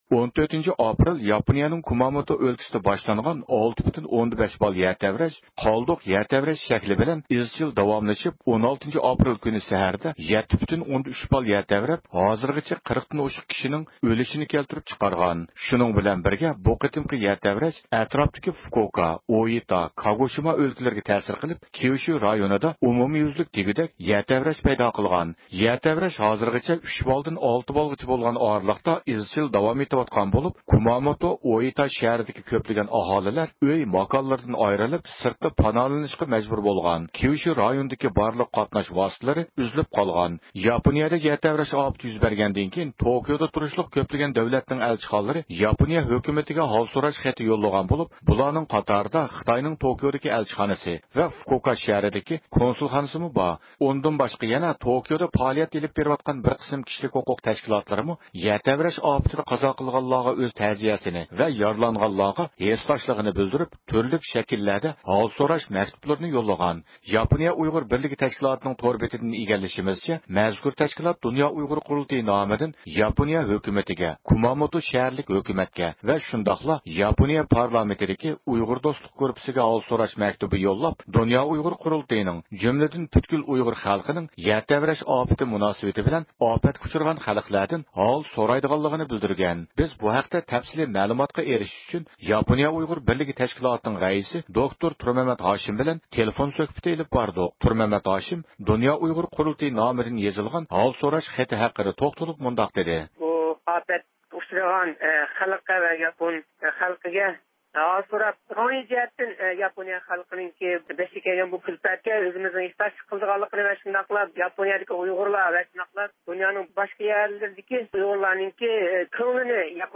تېلېفون سۆھبىتى ئېلىپ باردۇق.